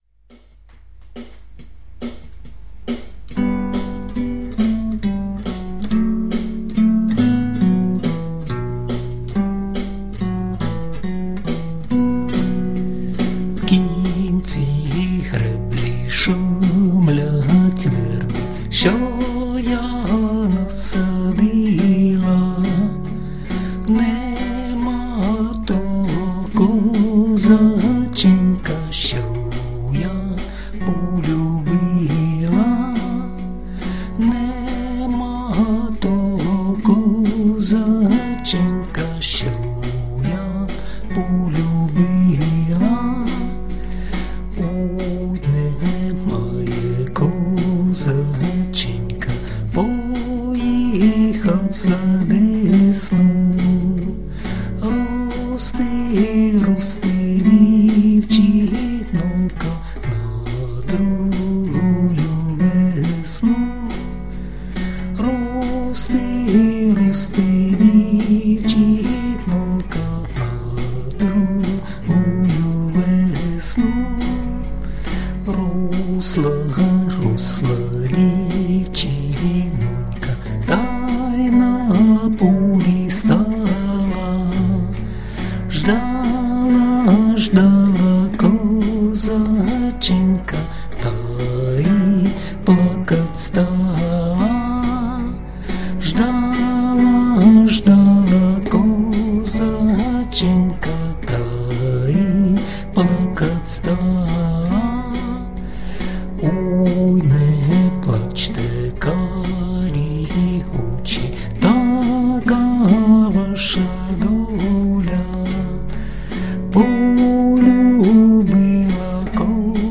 ../icons/valsslav.jpg   Українська народна пiсня